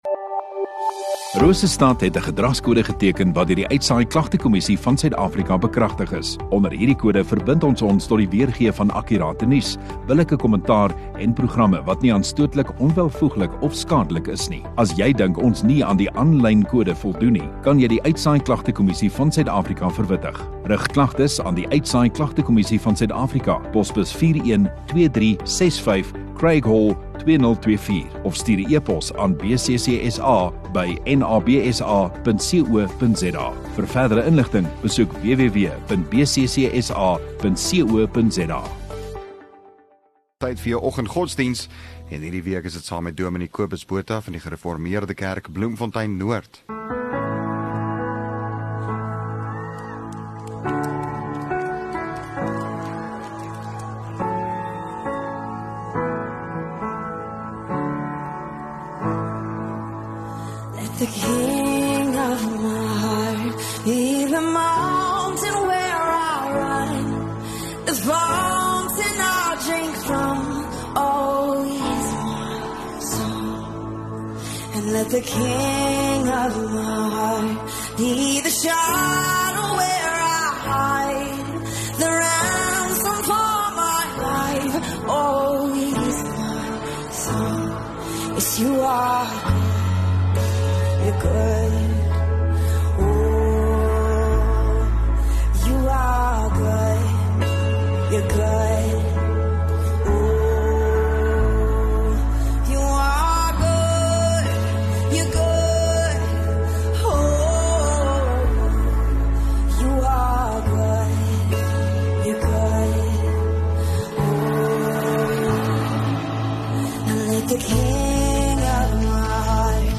6 Mar Donderdag Oggenddiens